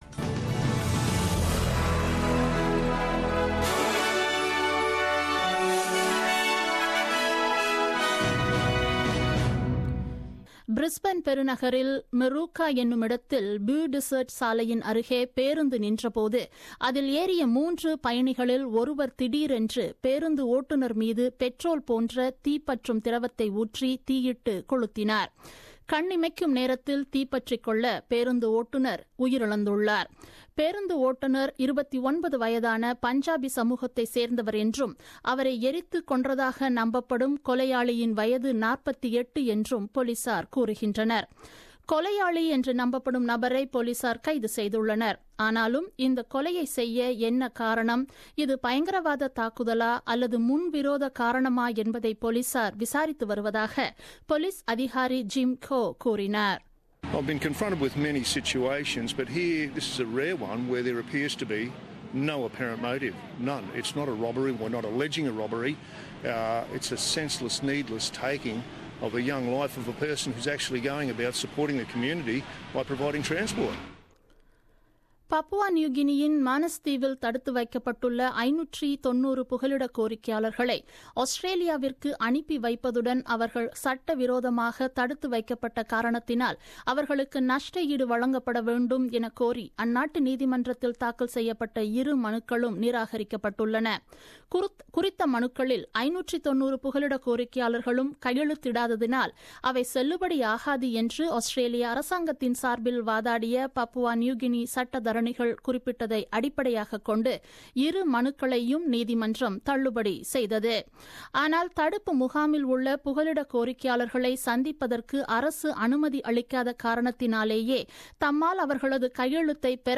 The news bulletin aired on 28 Oct 2016 at 8pm.